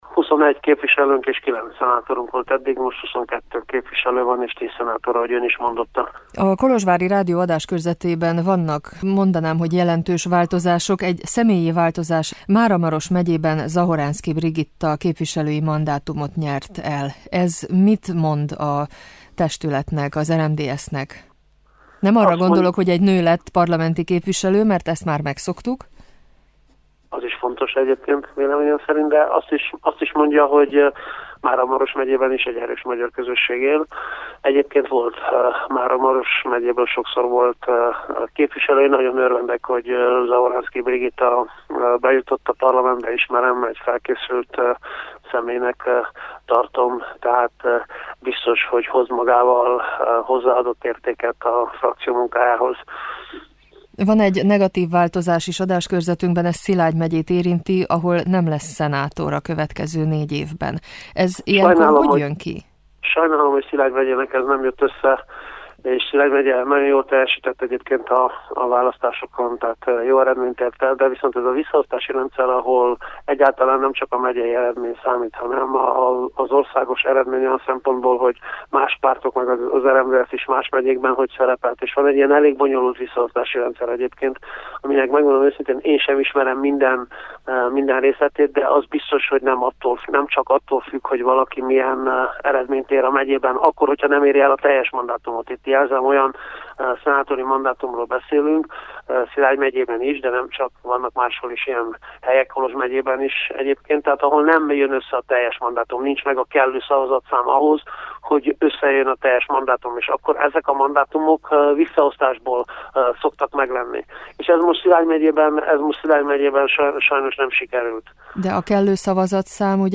Csoma Botond, az RMDSZ szóvivője válaszolt kérdéseinkre, többek között arra is, hogy mikor valószínű hogy megalakuljon a kormány.